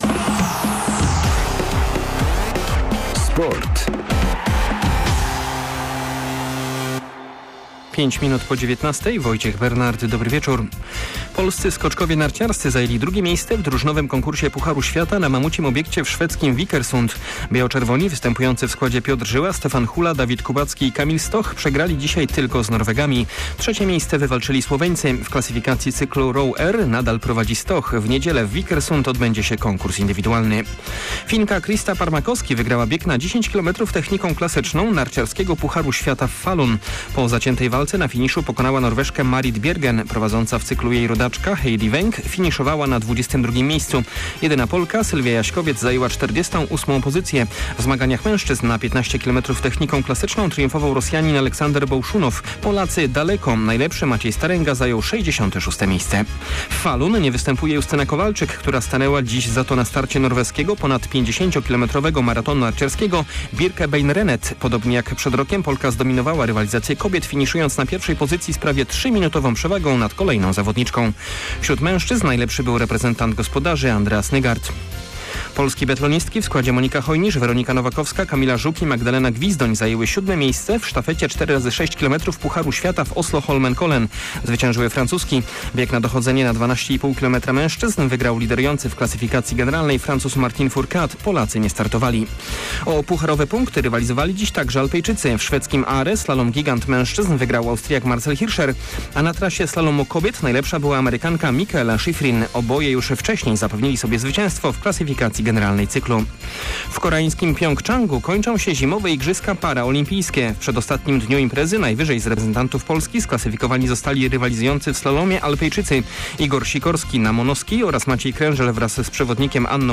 17.03 serwis sportowy godz. 19:05